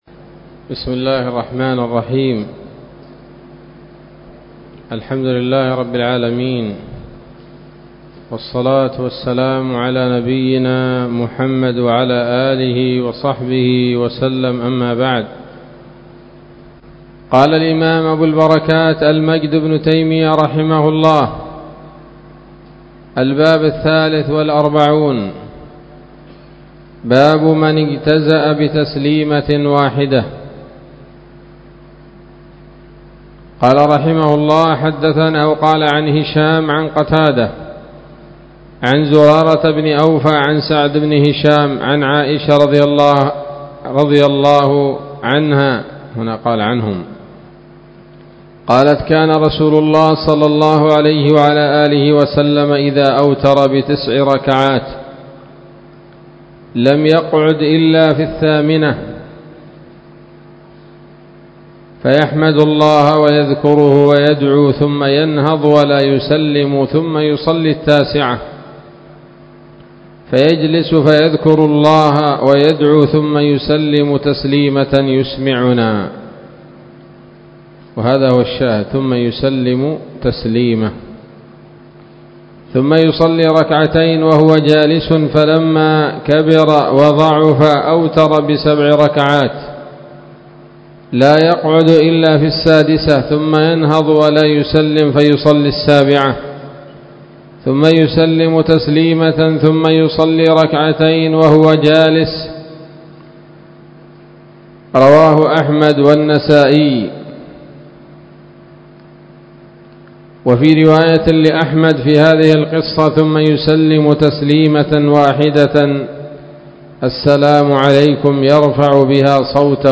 الدرس الرابع والتسعون من أبواب صفة الصلاة من نيل الأوطار